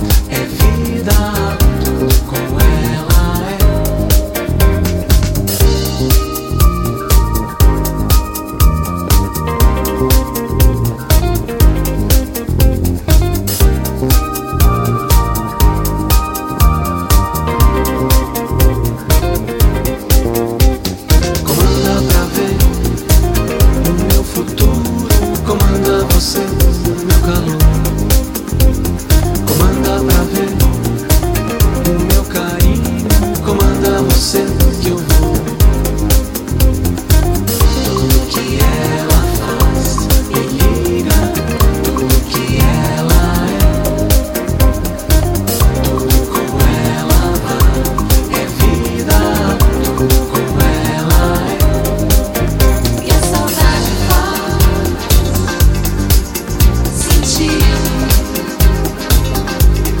adding a sensual feminine touch on backing vocals
plays disco-styled basslines
keyboards and guitars